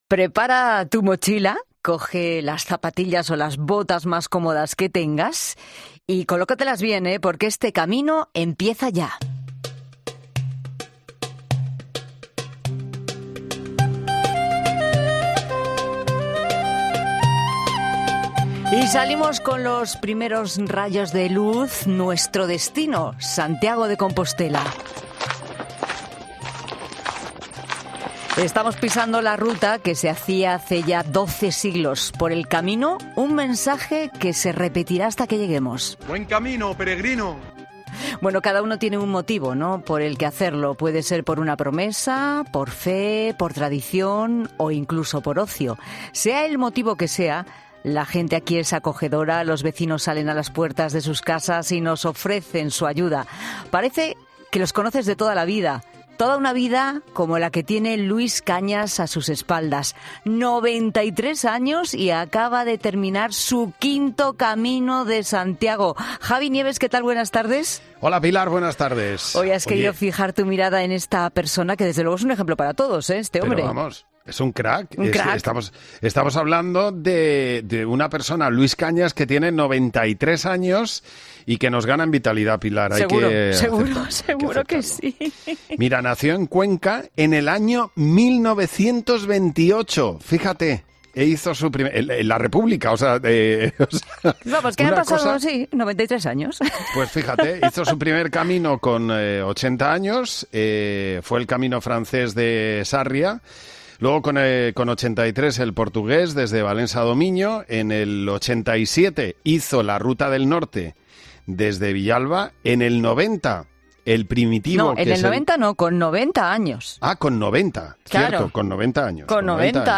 ha pasado por los micrófonos de 'La Tarde' de COPE para explicar su hazaña.